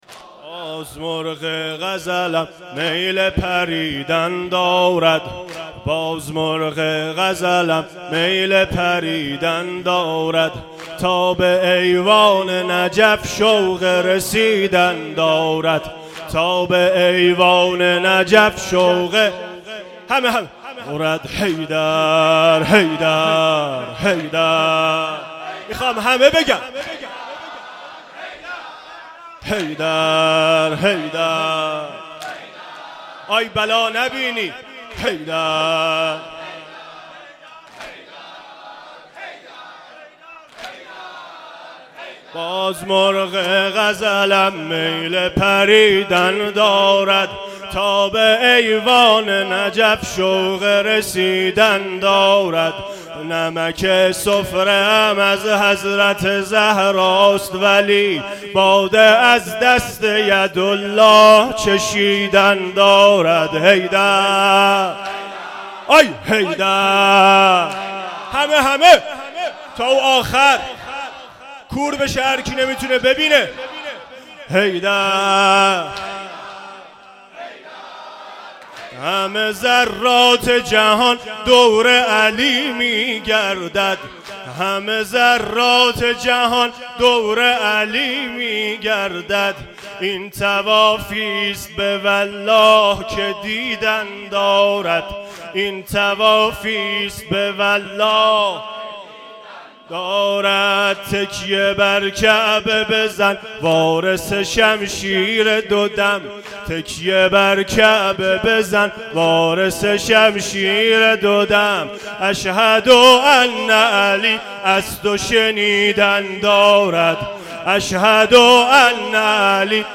واحد3شب سوم فاطمیه
مداحی